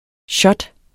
Udtale [ ˈɕʌd ]